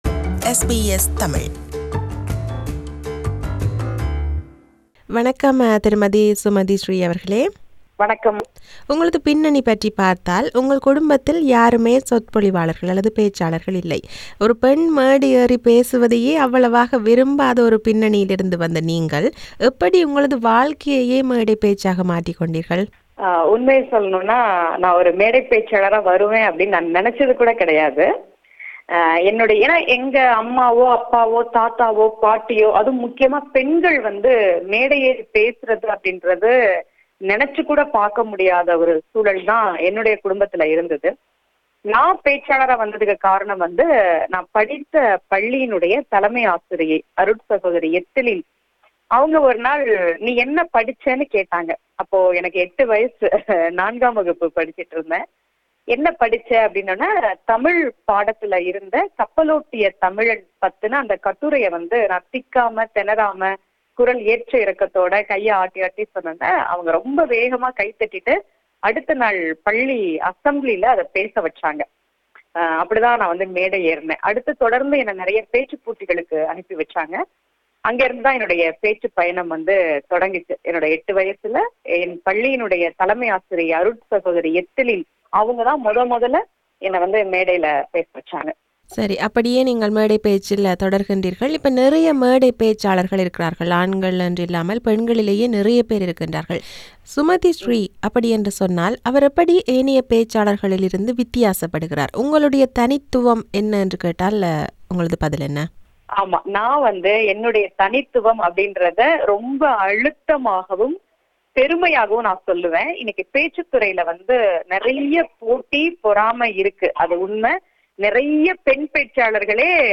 This is an interview with her.